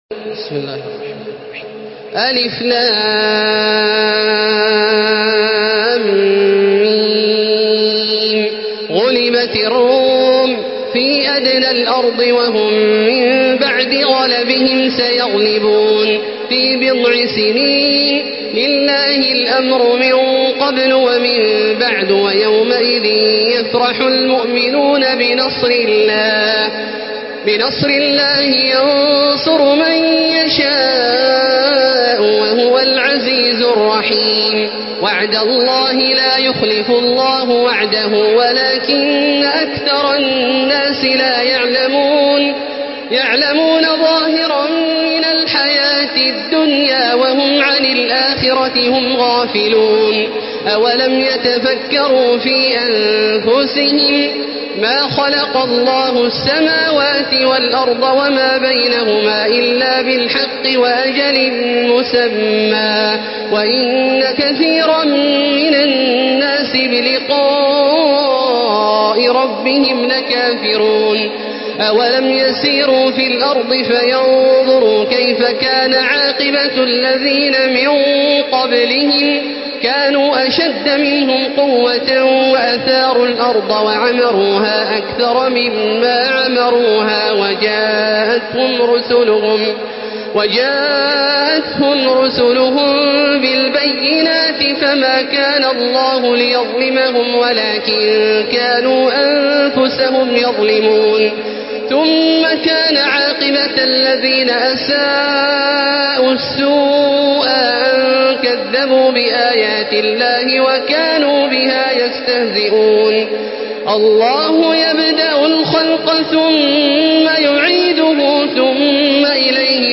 Surah Rum MP3 by Makkah Taraweeh 1435 in Hafs An Asim narration.
Murattal Hafs An Asim